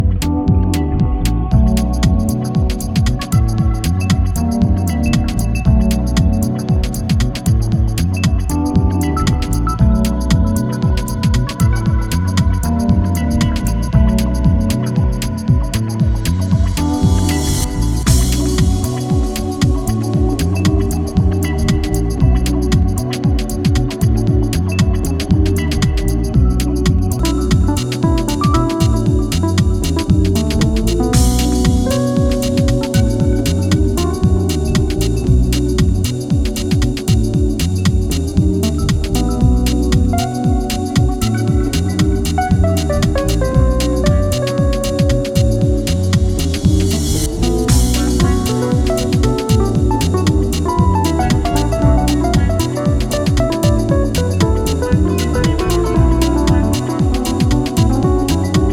ここでは、ジャジーで豊潤なメロディーが情感を駆り立てる、ウォームでグルーヴィーなディープ・ハウス群を展開。